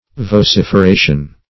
Vociferation \Vo*cif`er*a"tion\, n. [L. vociferatio: cf. F.